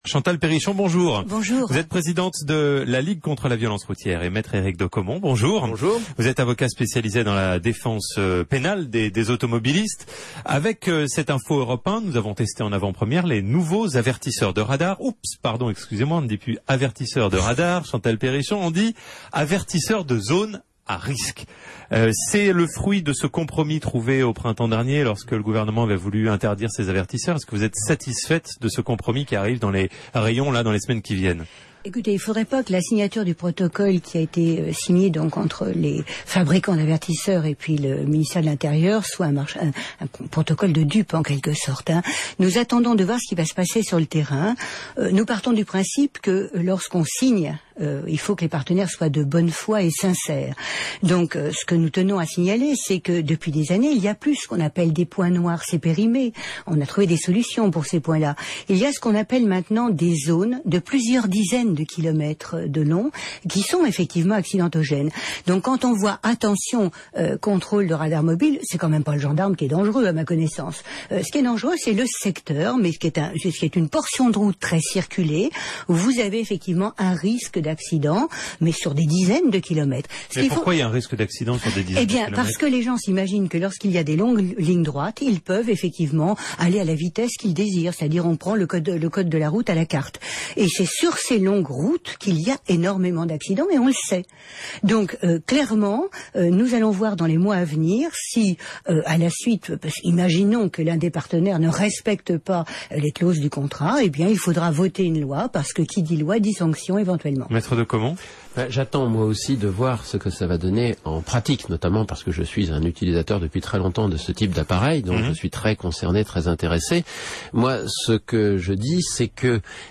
débat sur Europe 1 à propos du « remplacement » des avertisseurs de radars par des avertisseurs de zones à risques.